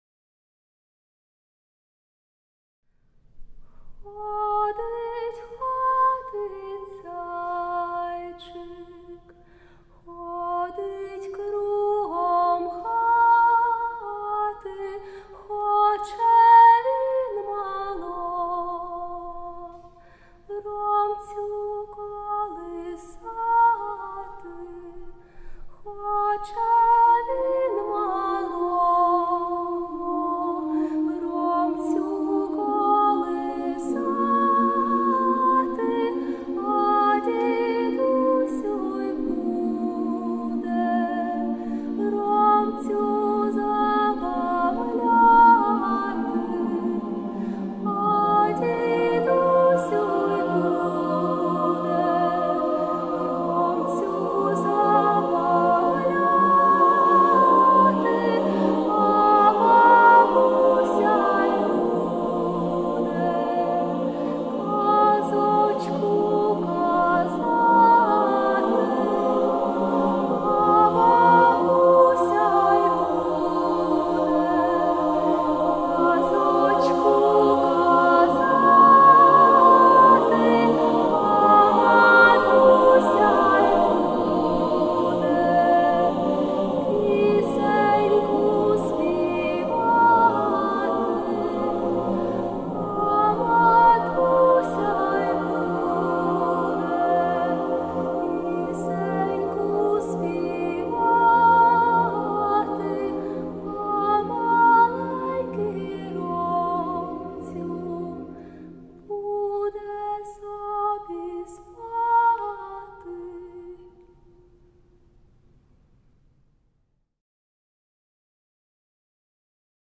SSA a cappella choir
The music is characterised by a soaring solo voice.